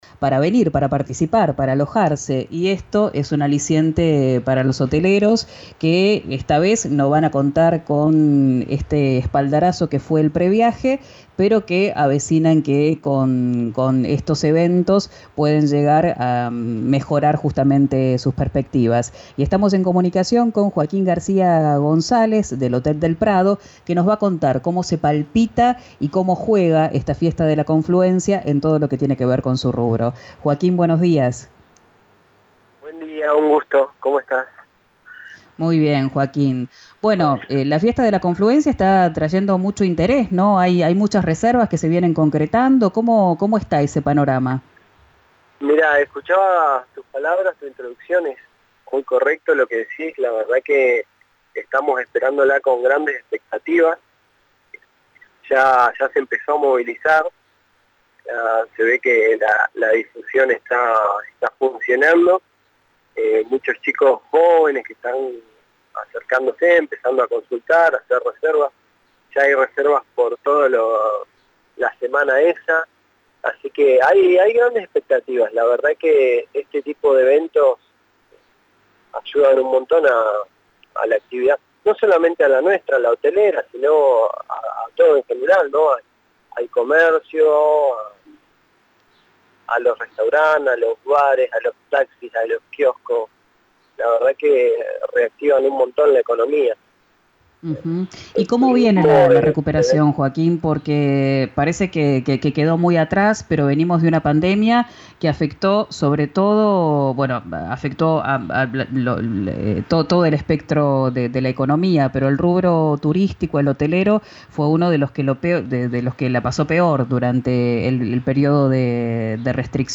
en declaraciones a Quien Dijo Verano, por RN Radio.